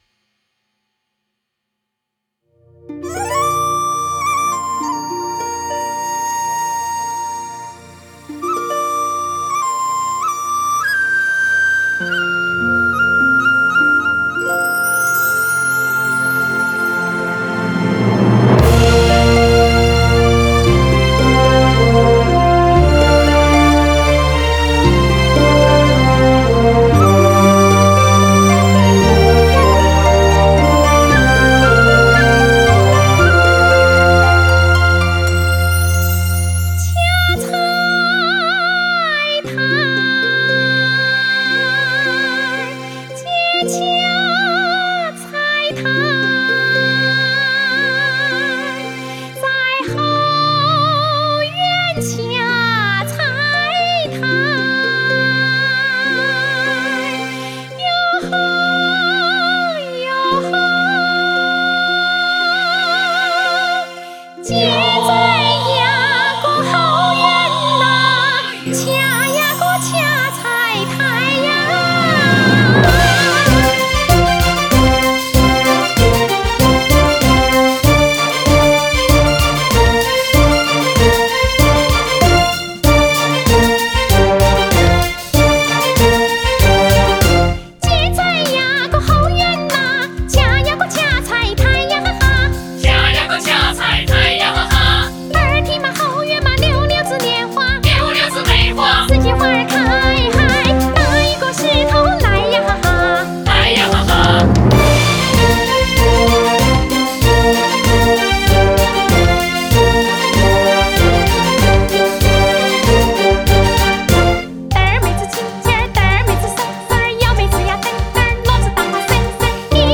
马渡山歌—《掐菜苔》欣赏_宣汉县文化馆